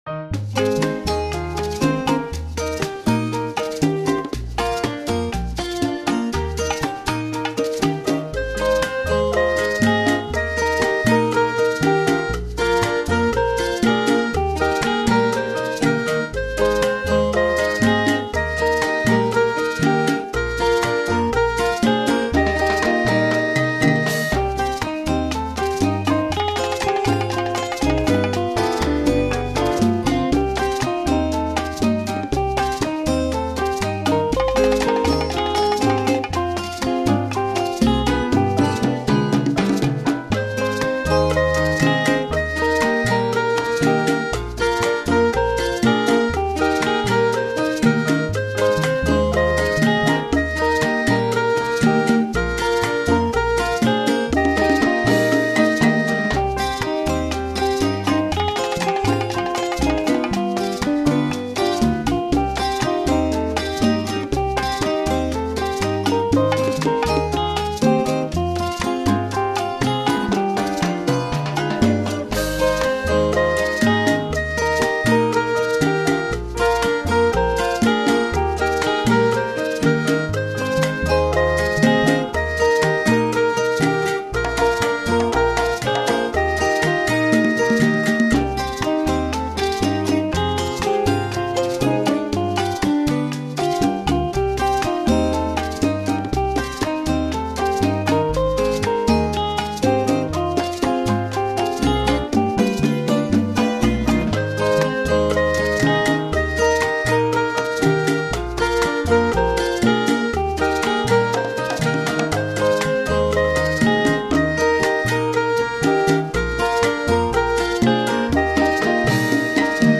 This is a bright Latin style song